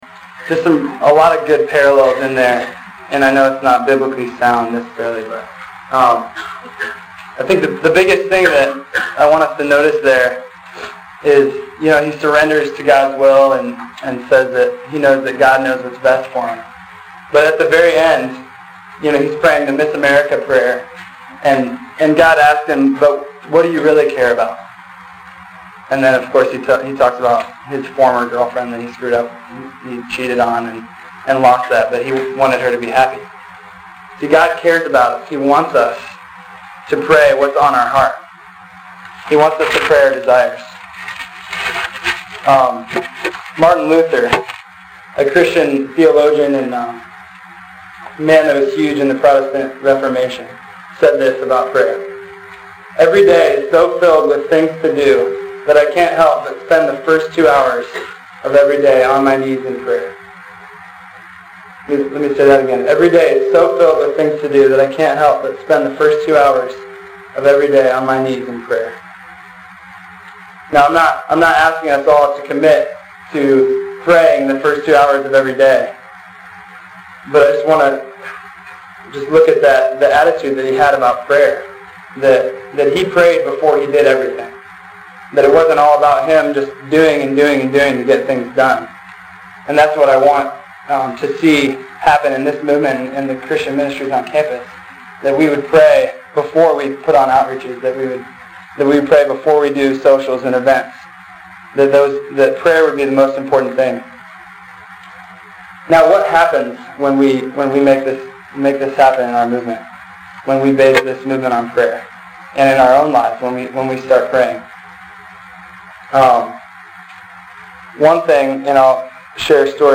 A Talk at the Campus Crusade Meeting on prayer